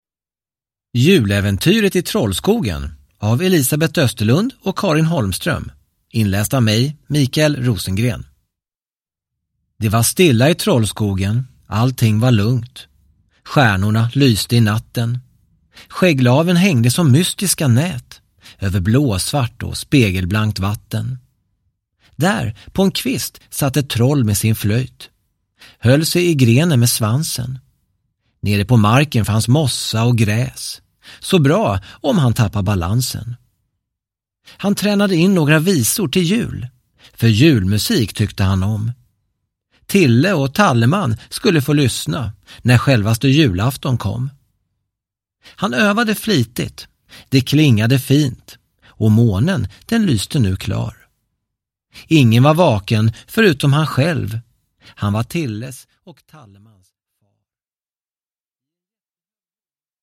Juläventyret i Trollskogen (ljudbok